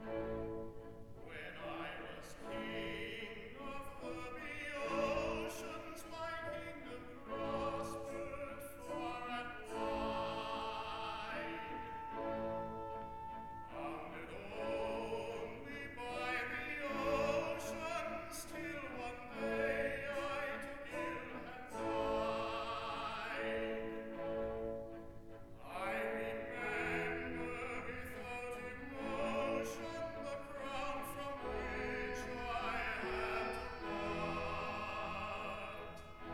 tenor
1960 stereo recording